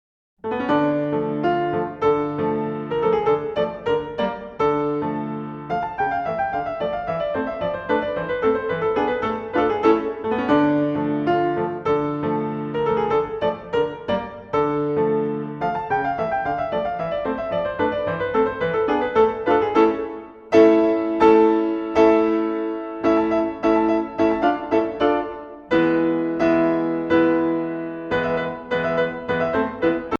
Voicing: Piano